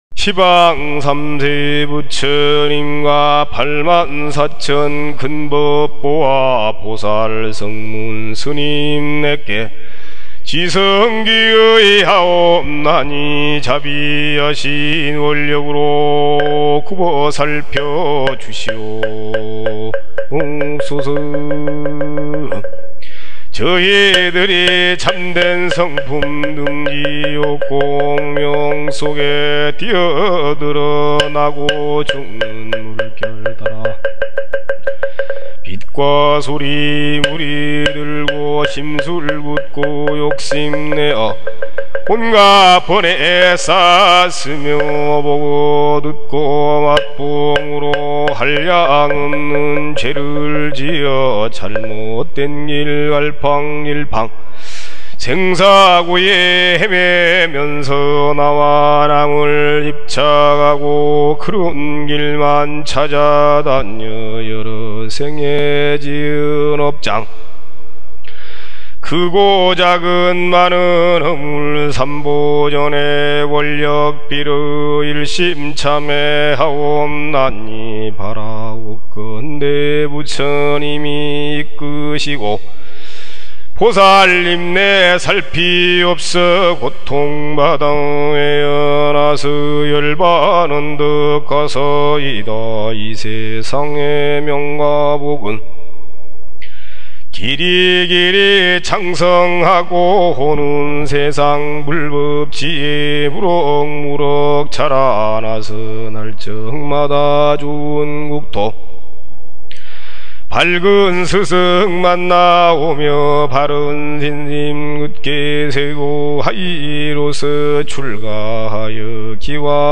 [스크랩] 염불 독경모음